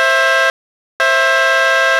ULTIMATE BLAST je kompaktní elektropneumatická fanfára, která v sobě spojuje dohromady dvojitou pneumatickou houkačku se silným a příjemným zvukem s velice jednoduchou montáží.
Stáhnout soubor fiamm_ultimate_blast_920621_24v_pneumatic_truck_compact_easy.wav